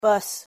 pronunciation-en-bus.mp3